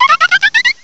direct_sound_samples
cry_not_fletchinder.aif